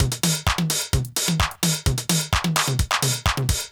CLF Beat - Mix 2.wav